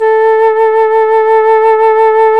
FLT FLUTE 22.wav